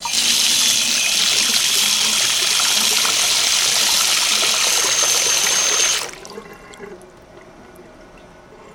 Faucet | Sneak On The Lot